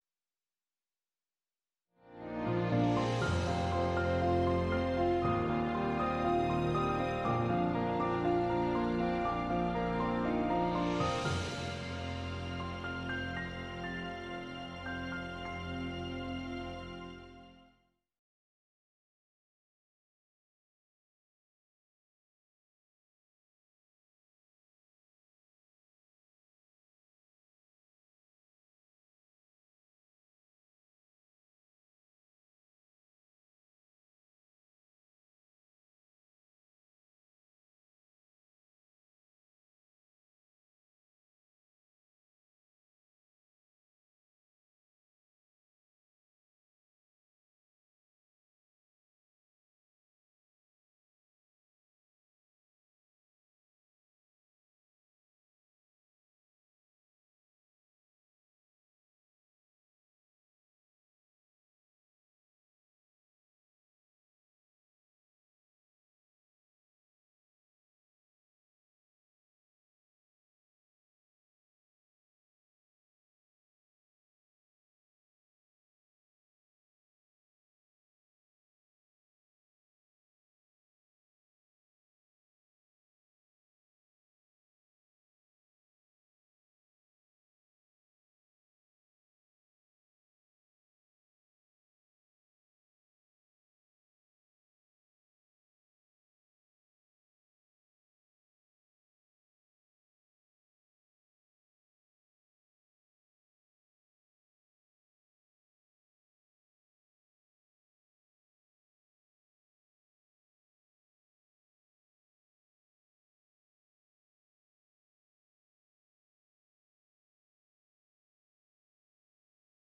Sermons
Given in Burlington, WA